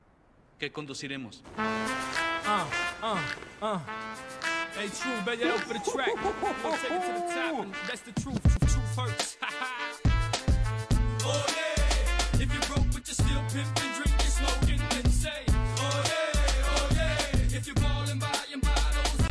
6 cilindros!